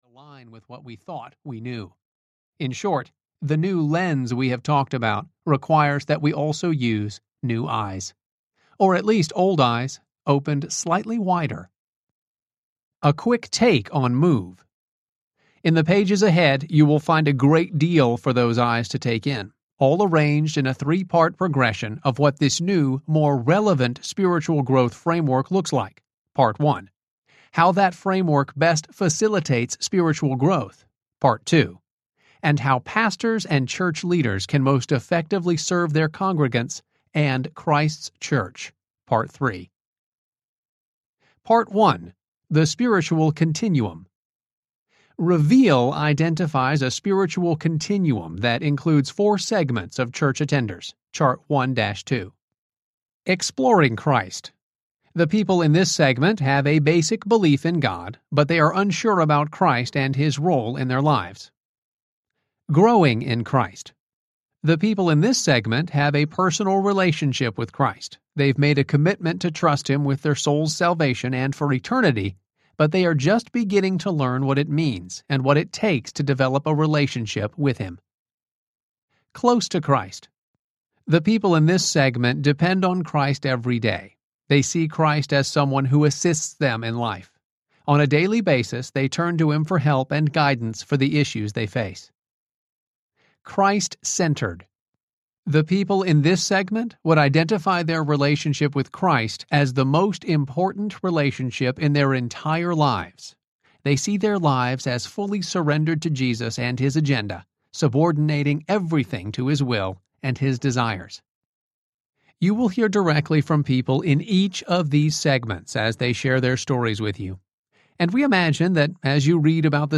MOVE Audiobook
Narrator
9.35 Hrs. – Unabridged